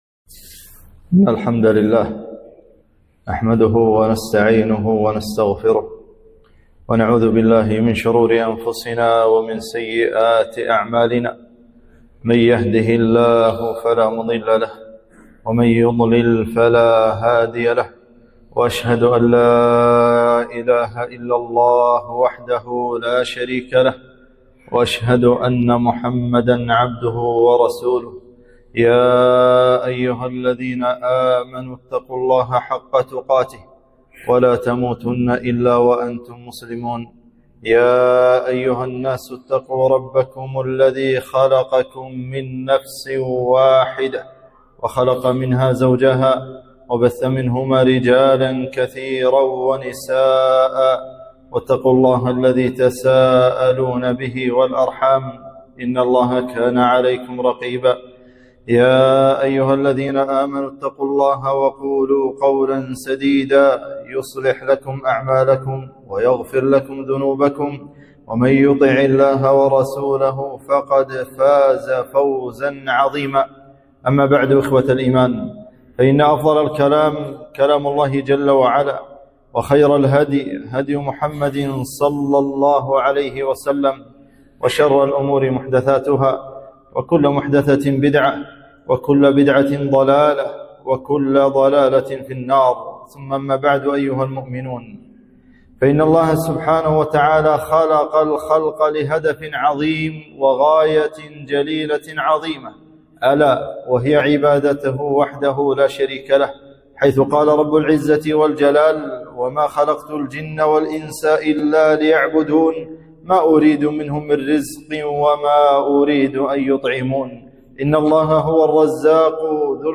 خطبة - أخطر أعضاء اللسان